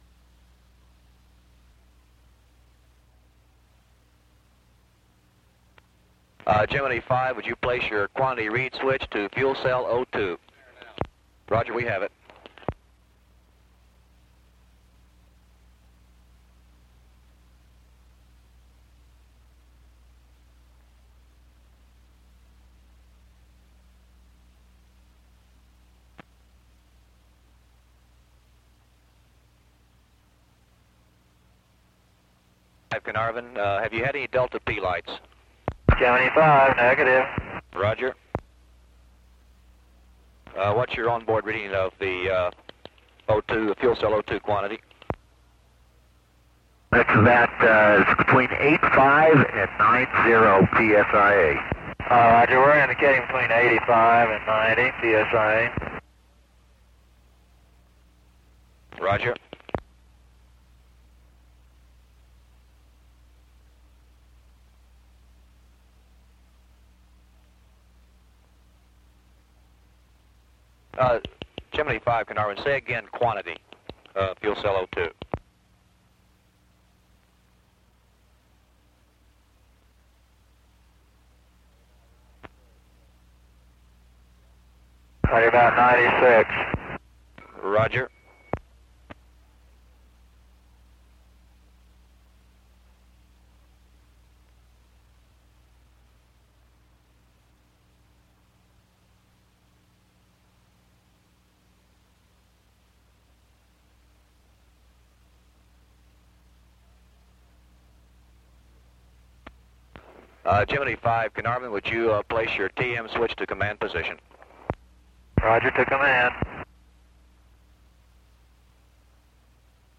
Gemini V audio – recorded at Carnarvon
The audio files of each pass are unaltered, however periods of silence between passes have been removed or reduced.